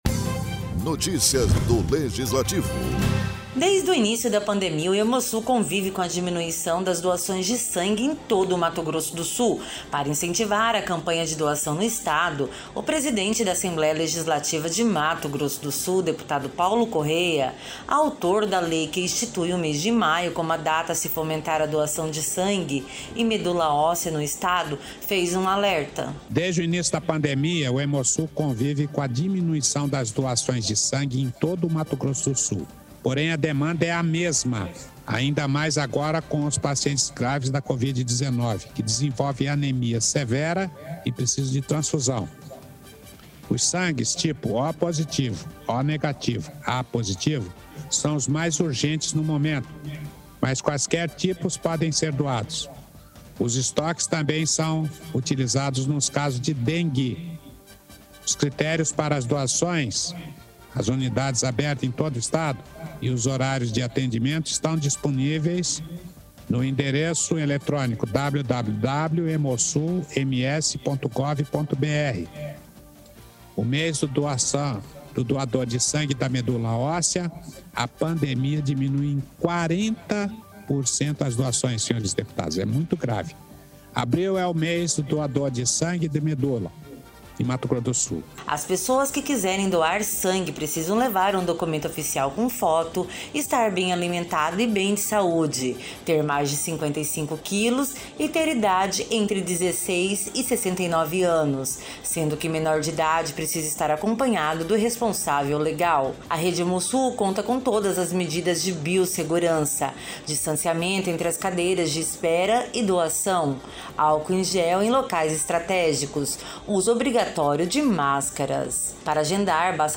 Durante a sessão plenária desta terça-feira, o presidente da Assembleia Legislativa de Mato Grosso do Sul, deputado Paulo Corrêa (PSDB), fez um alerta chamando a atenção de todos órgãos do governo, entidades de classe, associações, sociedade civil organizada, para efetivamente incentivar e concretizar a doação de sangue no Estado.